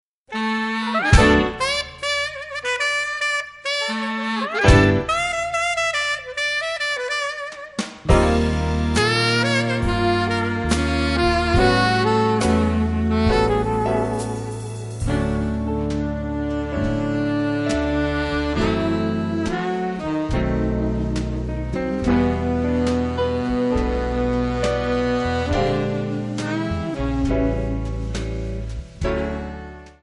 D
Backing track Karaoke
Pop, Oldies, Jazz/Big Band, 1950s